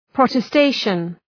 Προφορά
{,prɒtə’steıʃən}